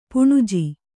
♪ puṇuji